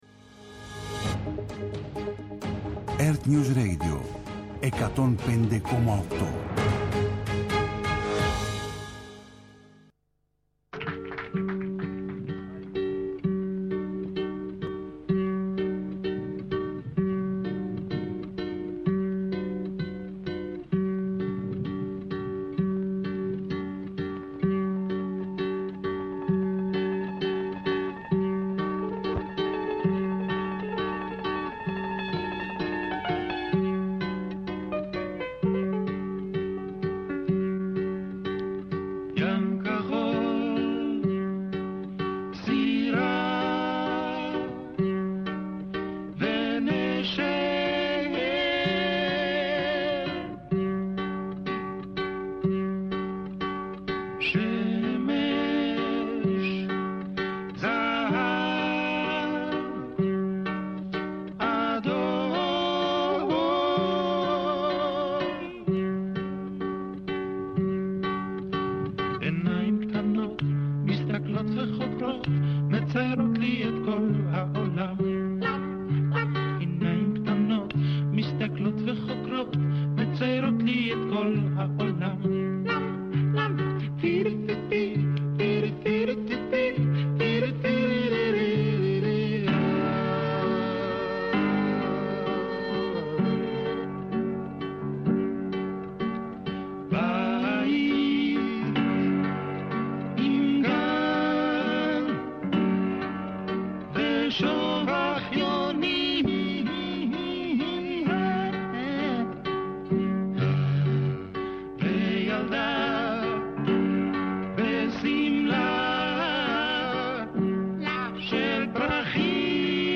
Απόψε στο στούντιο ο δημοσιογράφος